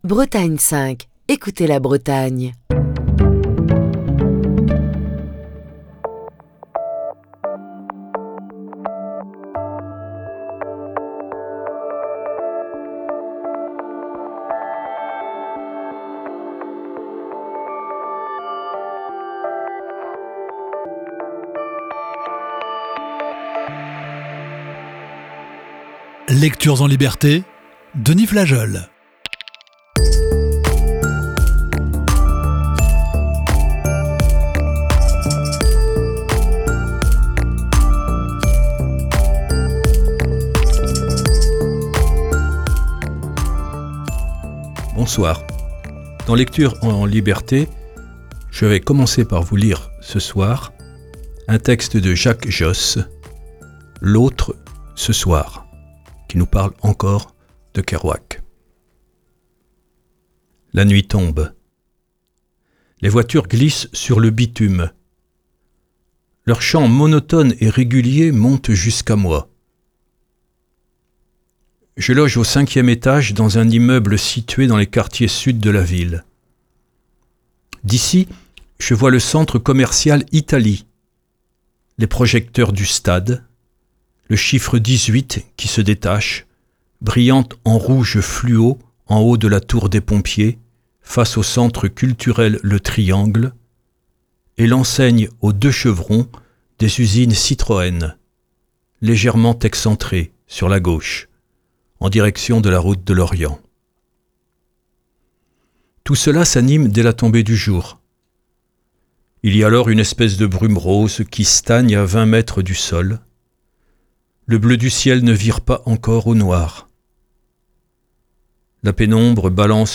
Nous poursuivons ce soir notre voyage avec la lecture d'un texte de Jacques Josse, "L'autre ce soir" et la première partie de "Sur la route" de Jack Kerouac.